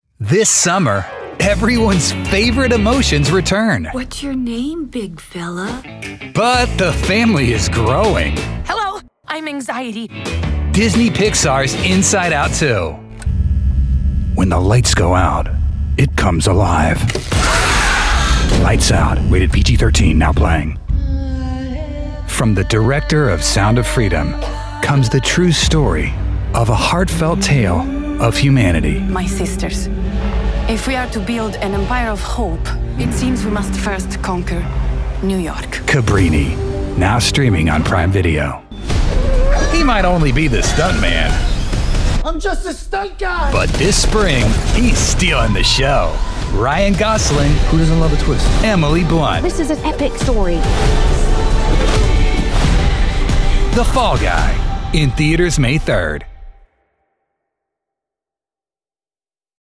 Bright, Upbeat, Youthful.
Movie Trailer